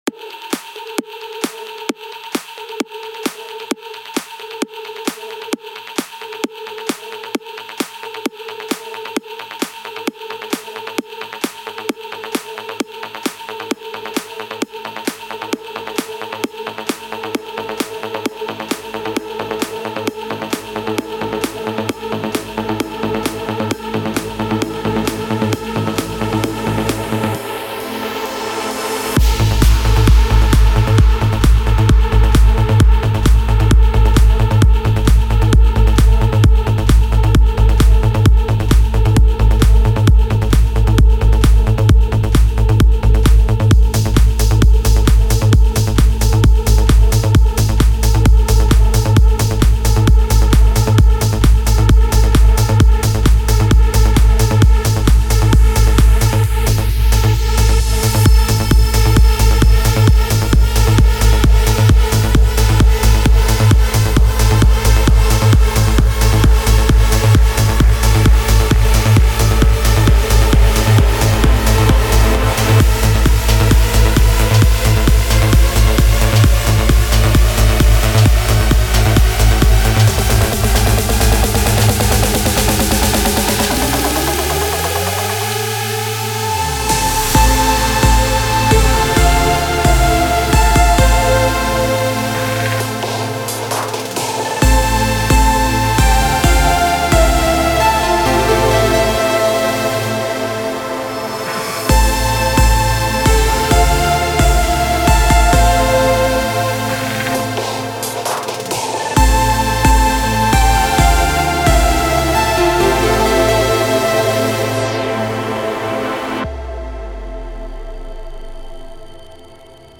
سبک پر‌انرژی , ترنس , موسیقی بی کلام , ورزشی